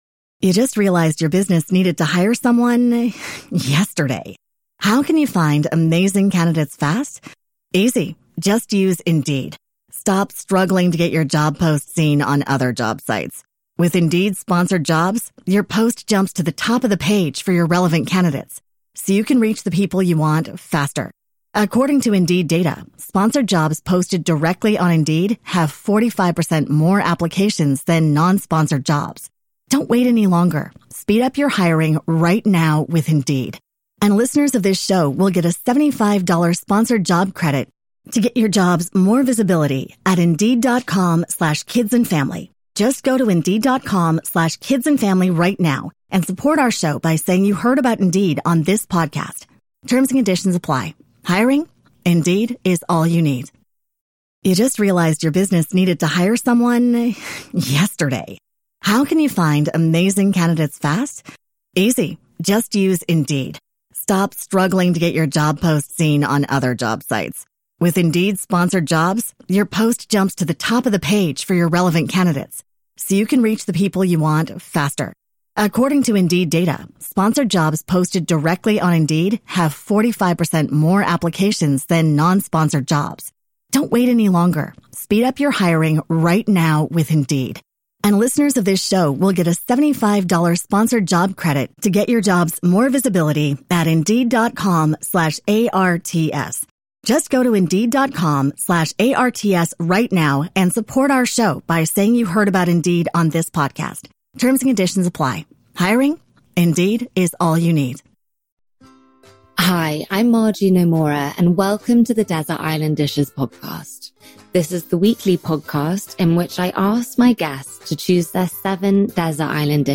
I was really nervous which I think you may be able to hear in my voice at the beginning but we had so many people there and everyone was so lovely and they all seemed to enjoy it so that made it even better and so now we can get planning some more.
So we recorded the podcast in the same manner – it’s the same other than the fact its been recorded in front of an audience and I’ve left in a bit of the intro so you can get a feel for what it was like.